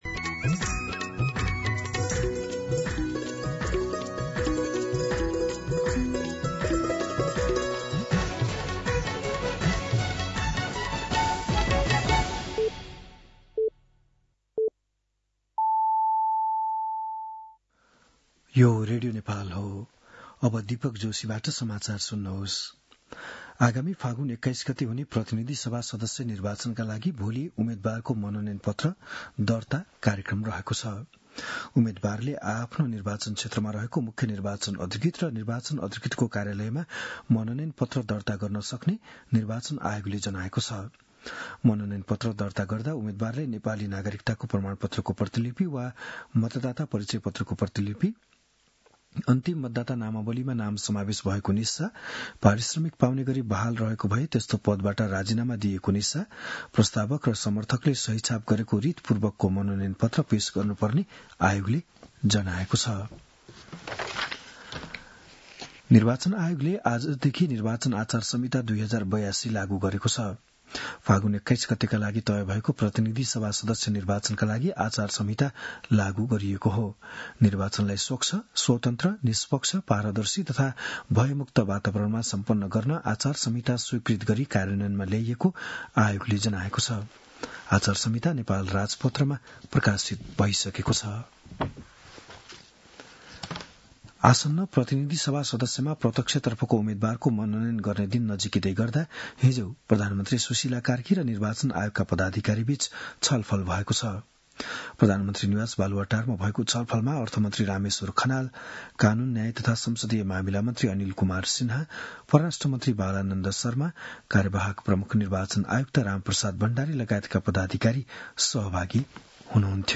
बिहान ११ बजेको नेपाली समाचार : ५ माघ , २०८२